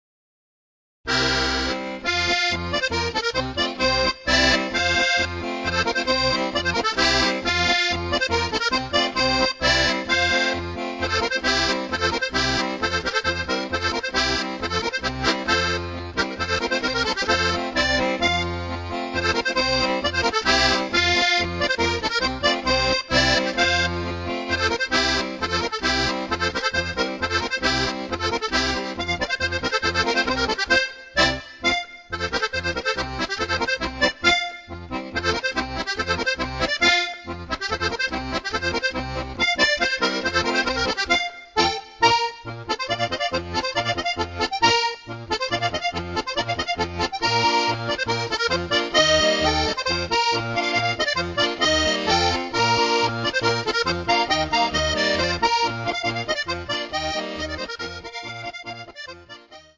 organetto diatonico